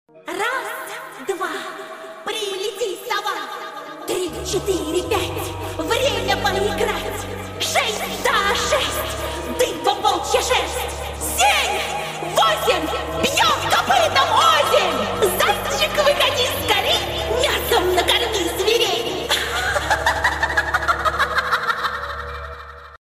жуткие , русские , пугающие , из игр , ost